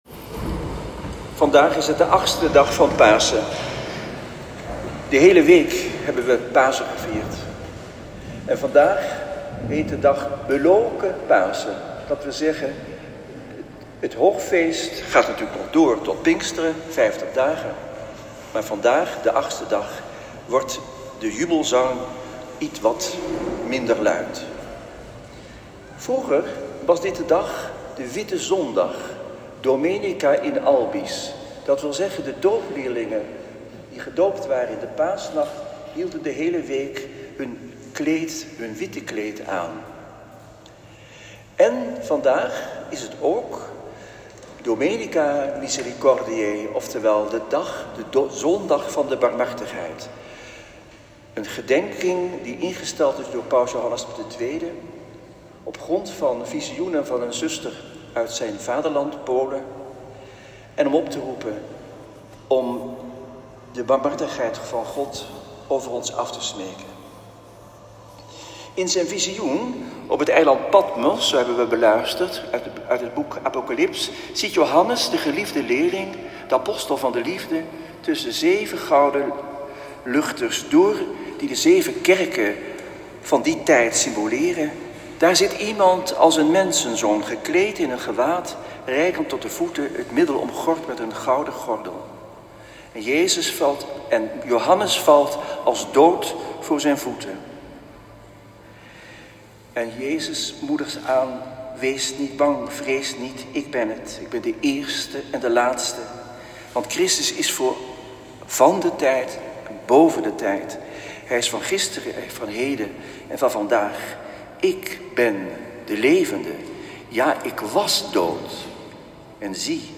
Preek-1.m4a